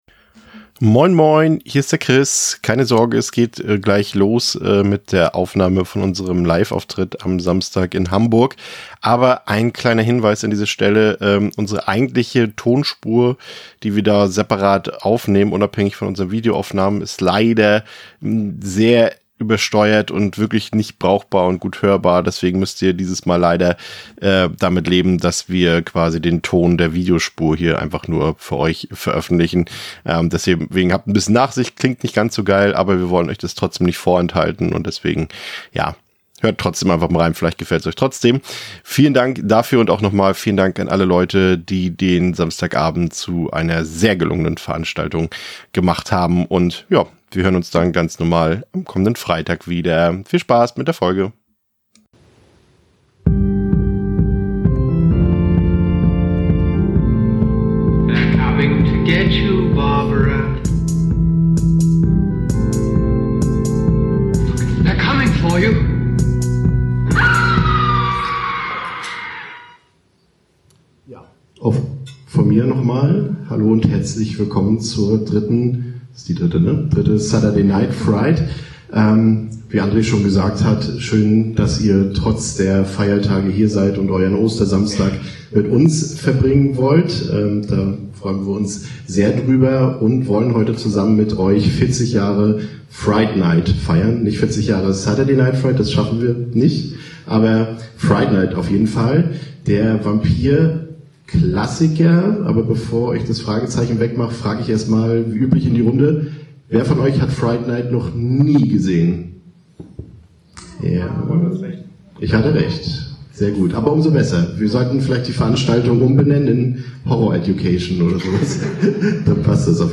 1 Reeling in the Right Deals: Sponsorships, Pro Staff, and Ambassadorships in Surf Fishing 45:29 Play Pause 14d ago 45:29 Play Pause Redă mai târziu Redă mai târziu Liste Like Plăcut 45:29 This week, you're stuck with my voice only in your ears. I want to talk about sponsorships in the surf fishing / influencer (ugh, I hate that word) world.